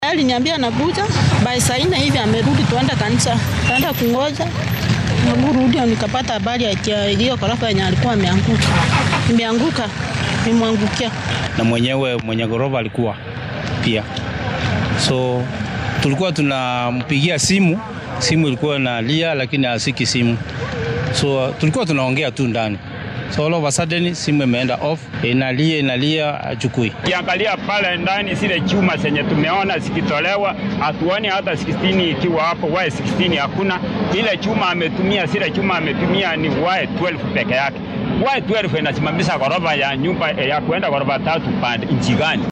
Waxaa la soo samatabixiyay saddex qof oo kale oo la geeyay isbitaalka guud ee Kisii si loo daaweeyo. Qaar ka mid ah goobjoogayaasha dhacdadaasi ayaa dareenkooda la wadaagay warbaahinta.